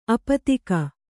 ♪ apatika